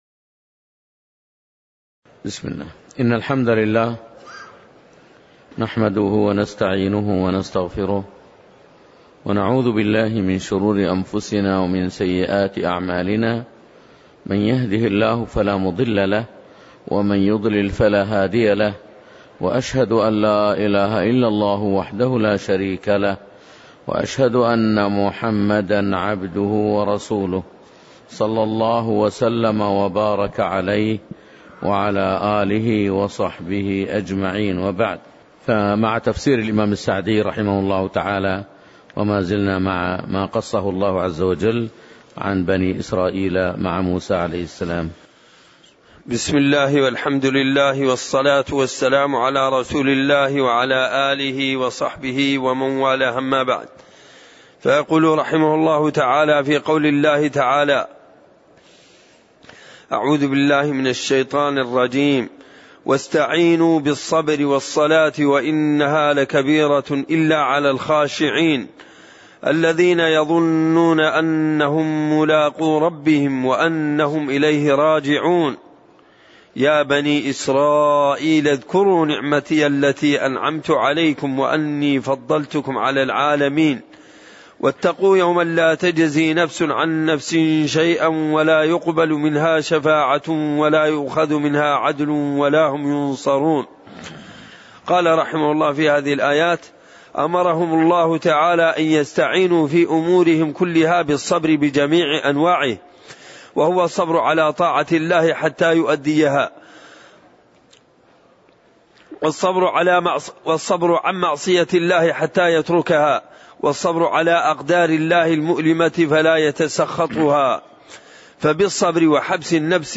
تاريخ النشر ٢٠ محرم ١٤٣٨ هـ المكان: المسجد النبوي الشيخ